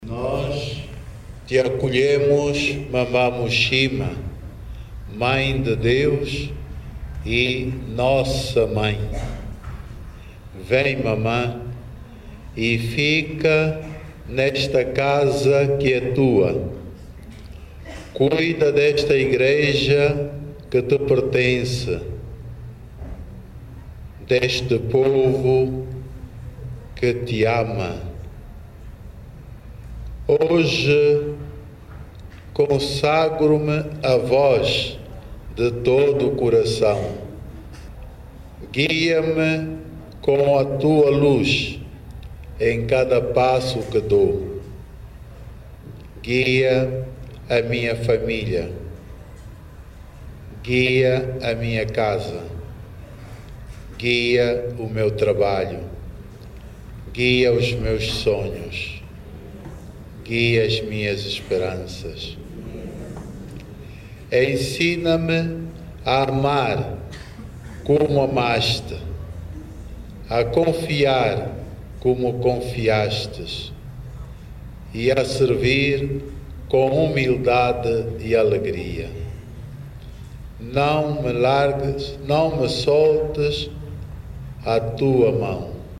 Já está em Luanda a imagem peregrina de Nossa Senhora da Muxima. O santuário de Nossa Senhora da Nazaré é a primeira comunidade acolher a imagem mariana e o momento foi testemunhado por Dom Filomeno do Nascimento Vieira Dias que em nome da arquidiocese dedicou uma intenção especial no momento de acolhimento.